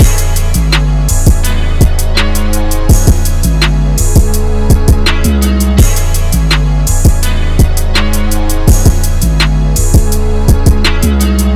C#m (D Flat Minor - 12A) Free sound effects and audio clips
• harmonic rap trap forever strings - C#m.wav
harmonic_rap_trap_forever_-_C_sharp_m_Mop.wav